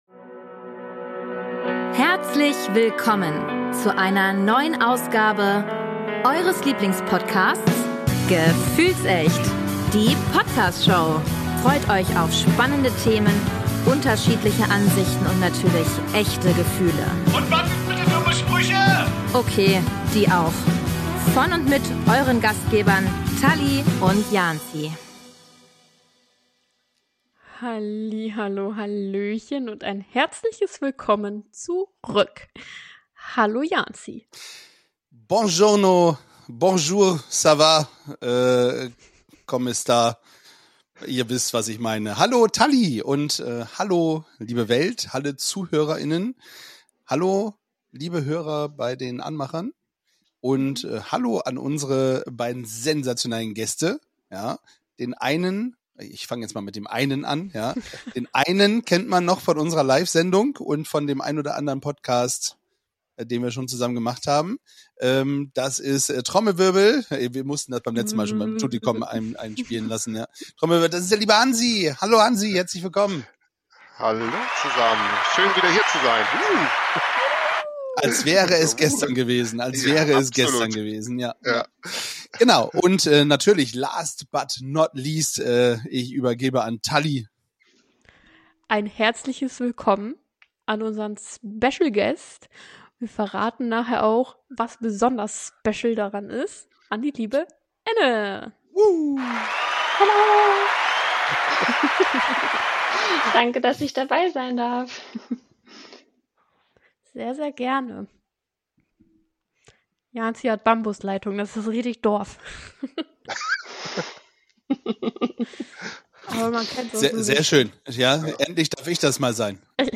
Gemeinsam reflektieren sie über die Highlights der Jubiläumsfeier, werfen einen Blick auf kommende Veranstaltungen des Heimatvereins und teilen persönliche Erlebnisse, die die Bedeutung von Zusammenhalt und Kreativität unterstreichen. Humorvolle Einlagen und inspirierende Gespräche machen diese Folge zu einem echten Wohlfühlmoment.